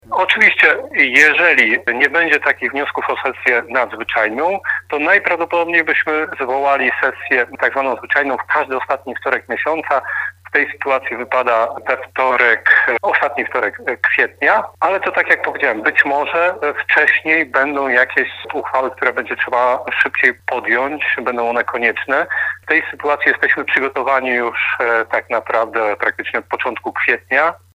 Piotr Barczak, przewodniczący rady miasta Zielona Góra wyjaśnia, że radni są gotowi do sesji.